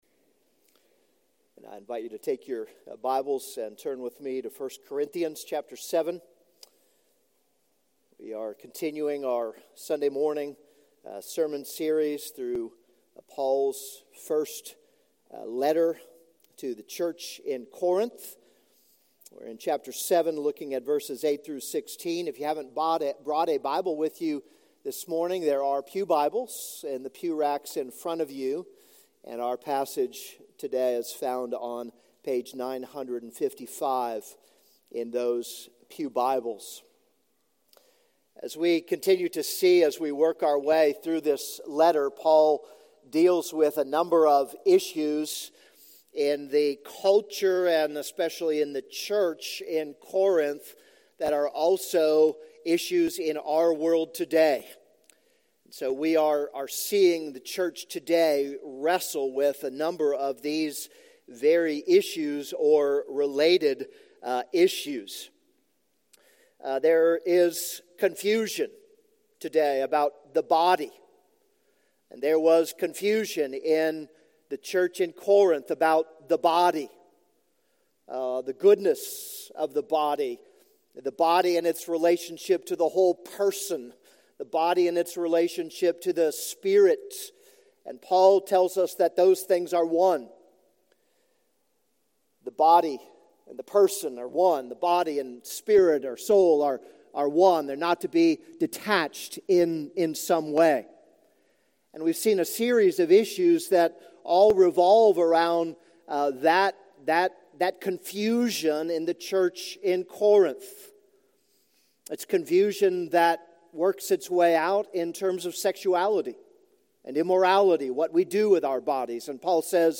This is a sermon on 1 Corinthians 7:8-16.